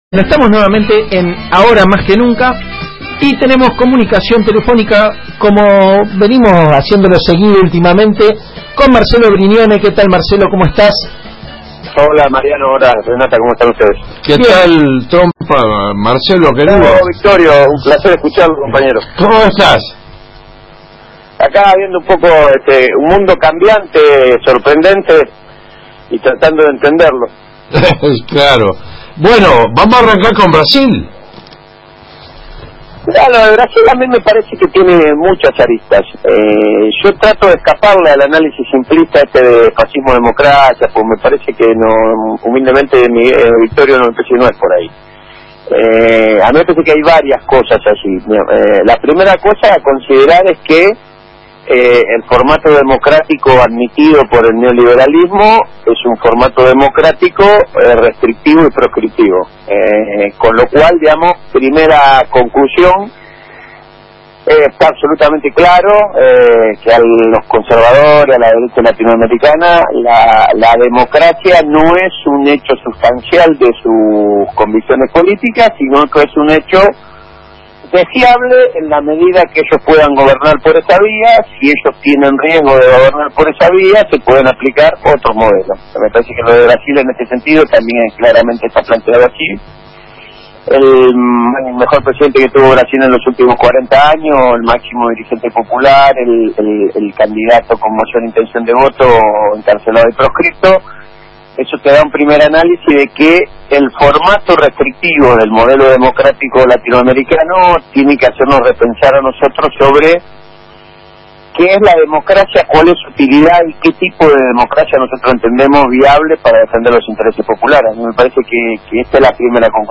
Entrevista
Entrevista en el Programa Ahora mas que Nunca de Radio AM 1330 de Rosario, Santa Fe, Argentina